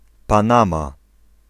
Ääntäminen
Etsitylle sanalle löytyi useampi kirjoitusasu: Panama panama Synonyymit boater Ääntäminen US US UK : IPA : /ˈpæn.ə.mɑː/ US : IPA : /ˈpæn.ə.mɑː/ Lyhenteet ja supistumat (laki) Pan.